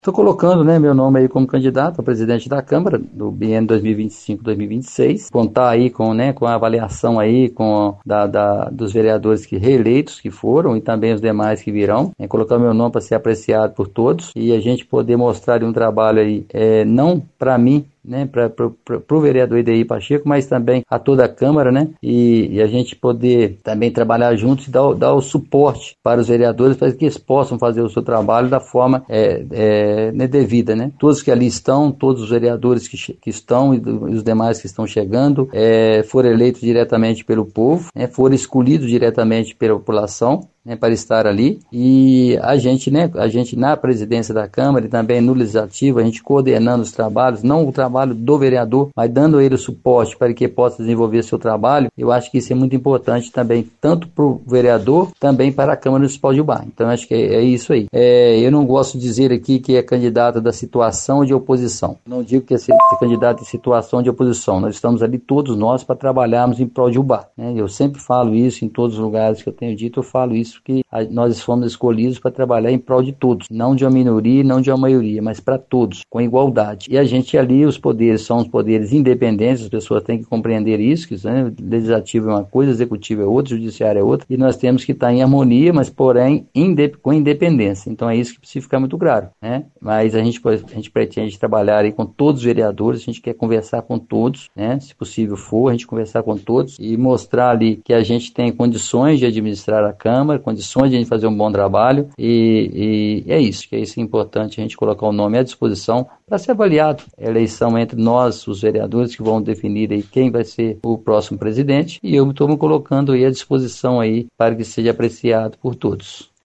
Já o vereador Edeir Pacheco, atual vice-presidente da Câmara, destacou em áudio enviado a nossa reportagem que, se for eleito, pretende oferecer suporte aos demais vereadores.